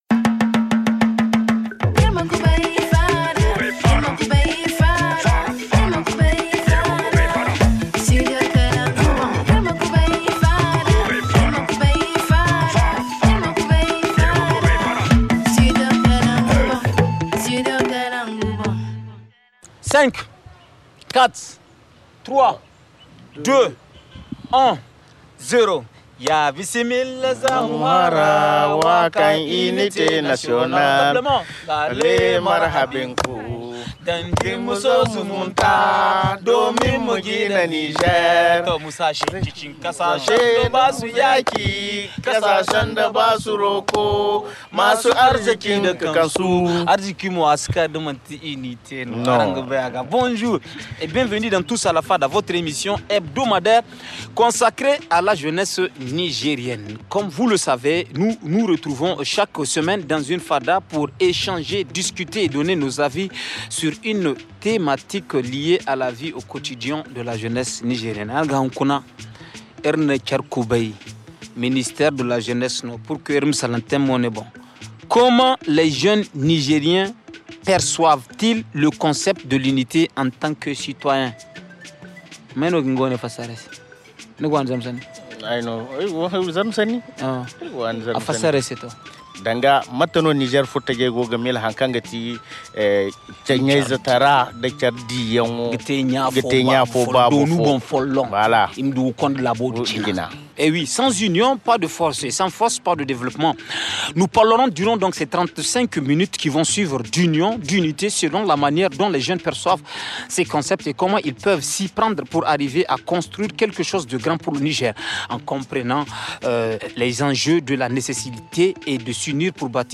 L’unité nationale est au cœur de notre fada de ce soir. Dans le jardin du ministère de la jeunesse